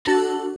Index of /phonetones/unzipped/LG/A200/Keytone sounds/Sound2